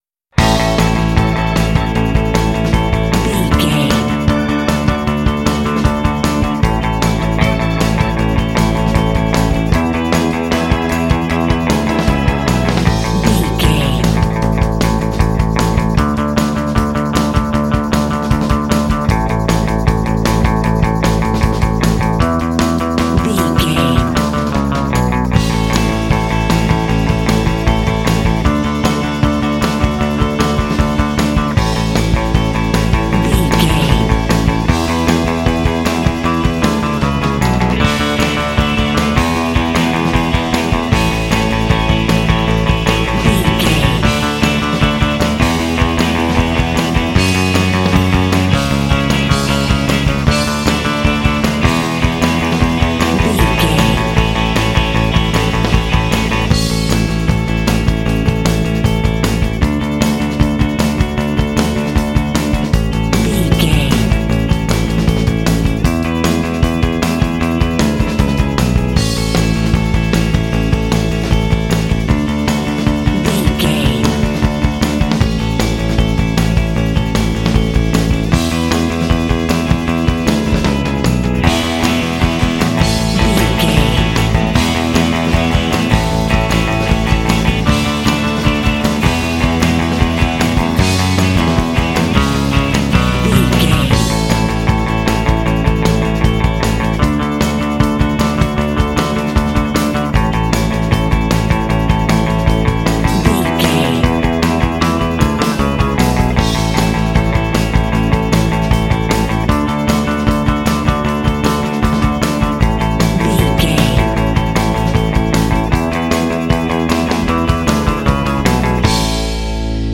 Uplifting
Ionian/Major
energetic
joyful
electric organ
bass guitar
electric guitar
drums
classic rock
alternative rock